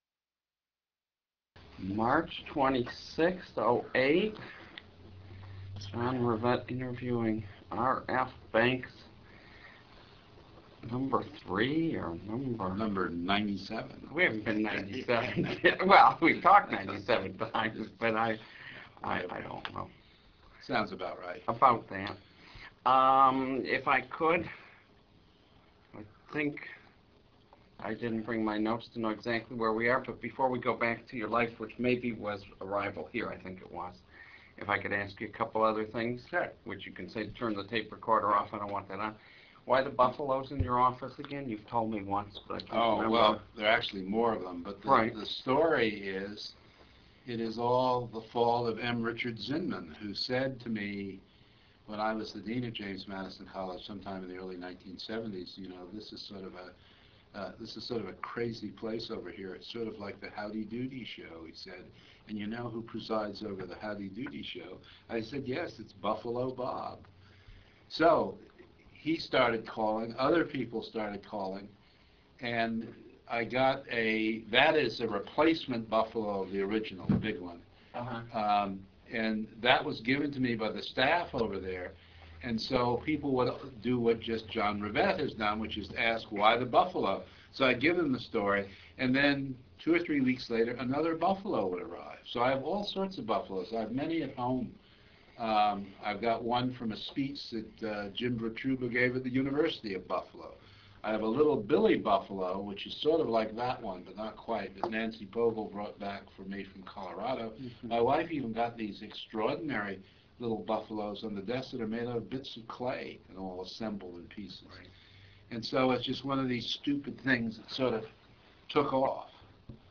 Fourth of seven interviews.